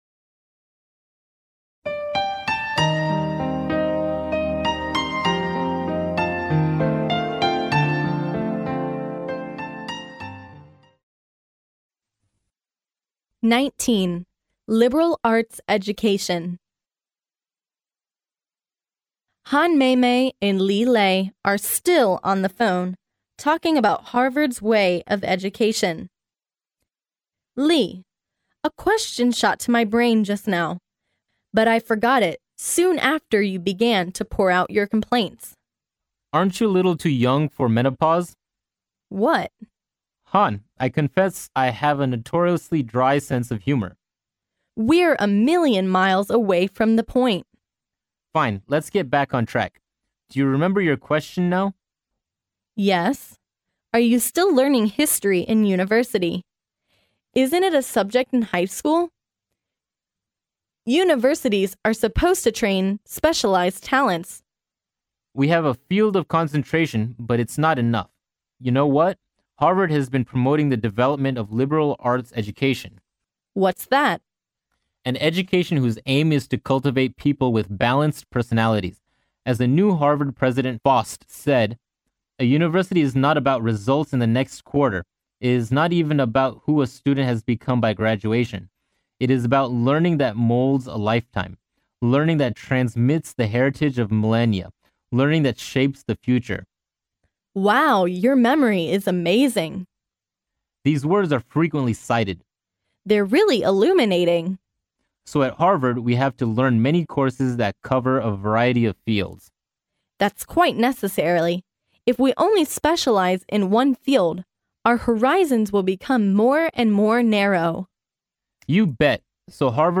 Han Meimei and Li Lei are still on the phone, talking about Harvard’s way of education.